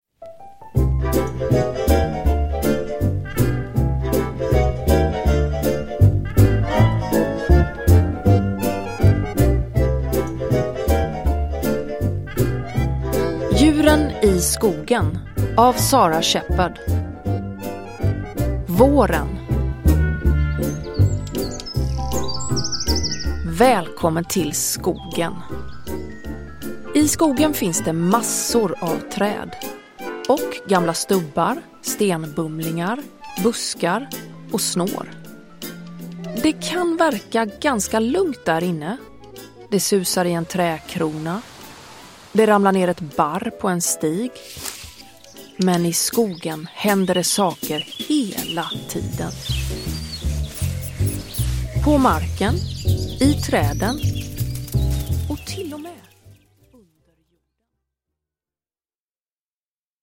Djuren i skogen - Våren – Ljudbok – Laddas ner